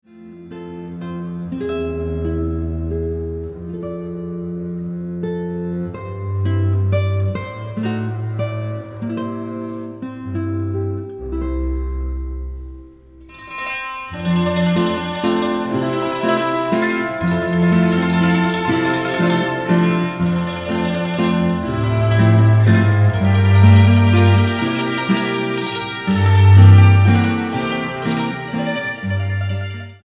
guitarra
contrabajo